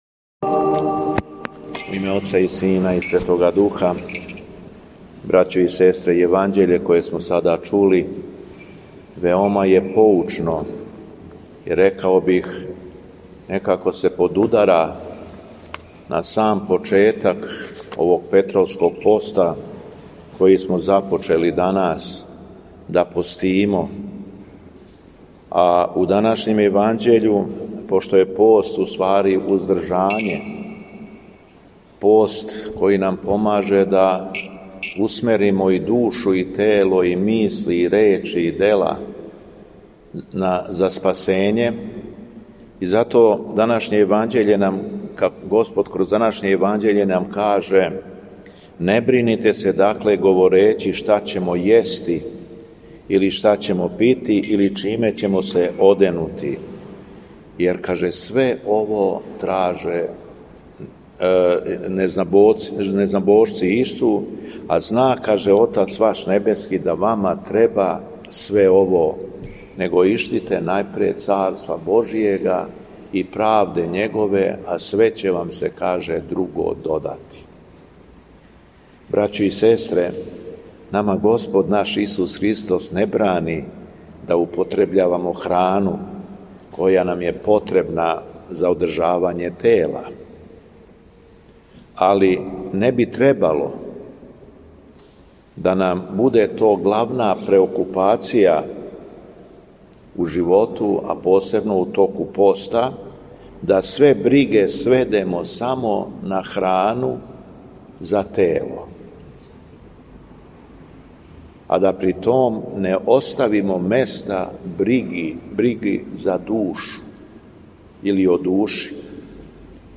Беседа Његовог Високопреосвештенства Митрополита шумадијског г. Јована
У наставку свете литургије после прочитаног Јеванђеља, Његово Високопреосвештенство Митрополит шумадијски обратио се верном народу надахнутом беседом: